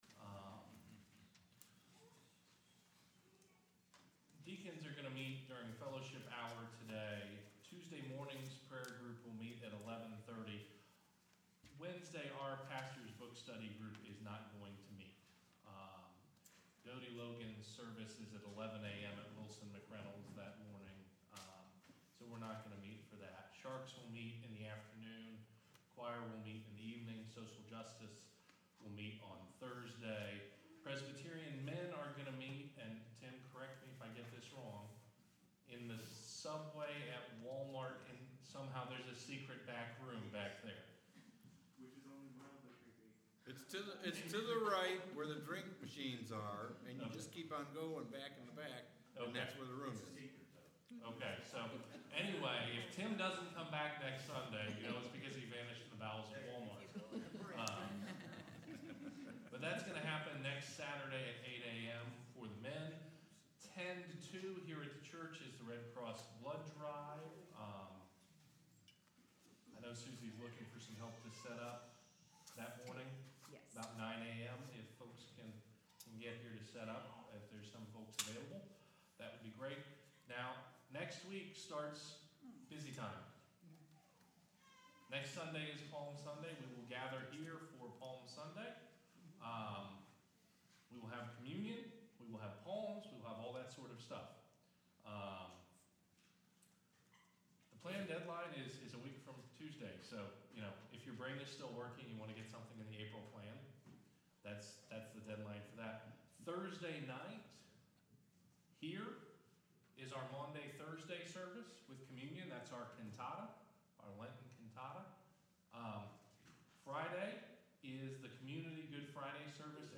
Worship from March 13, 2016